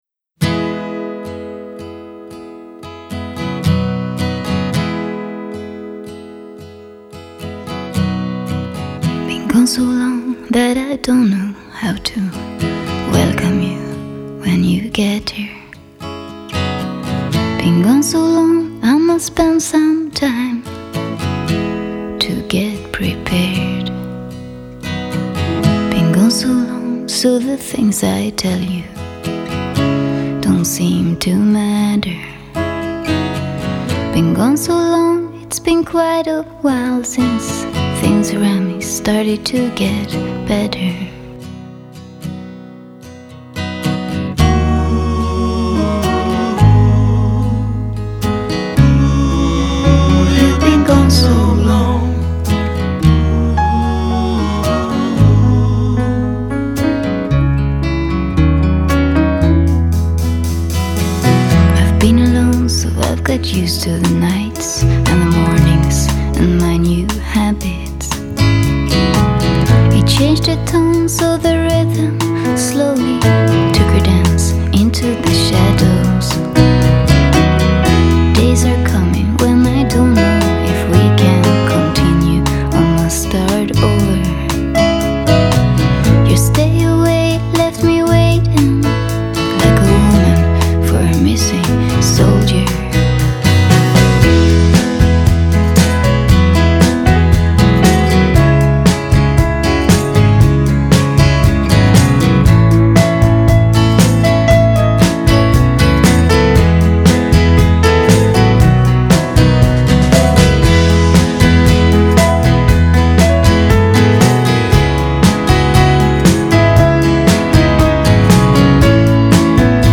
장르: Jazz, Pop
스타일: Vocal, Ballad